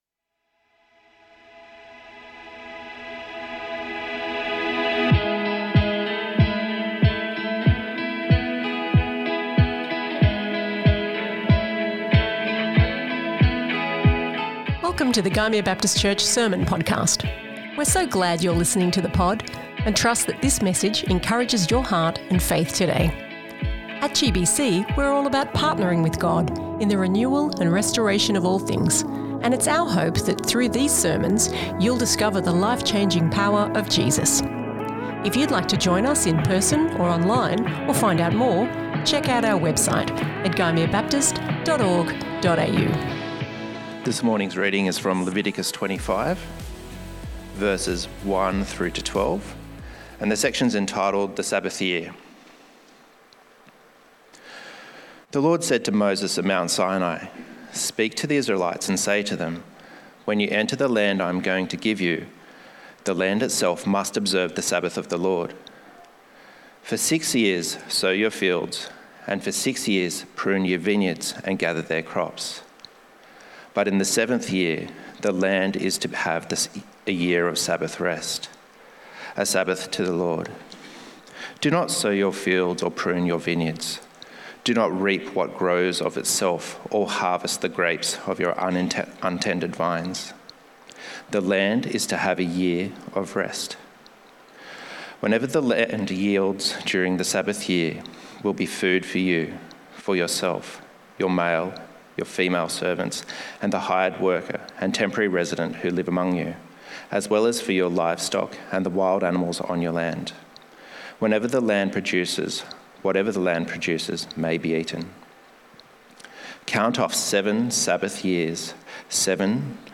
GBC | Sermons | Gymea Baptist Church